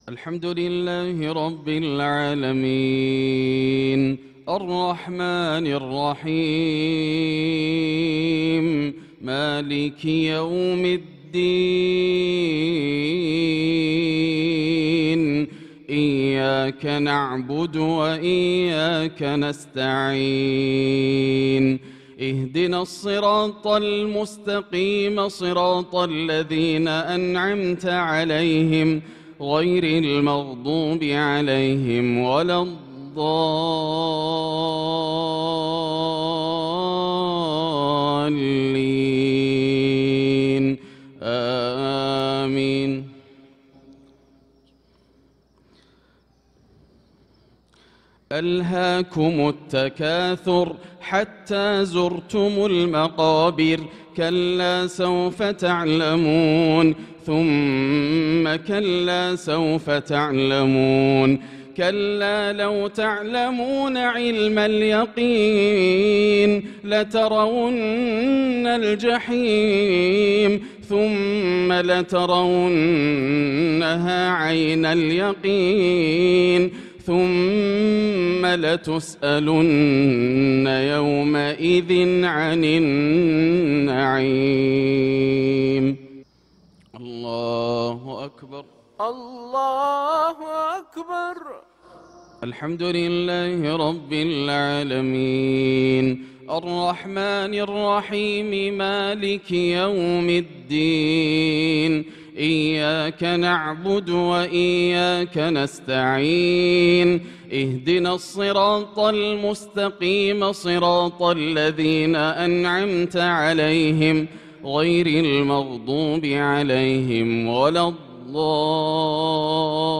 صلاة المغرب للقارئ ياسر الدوسري 11 ذو القعدة 1445 هـ
تِلَاوَات الْحَرَمَيْن .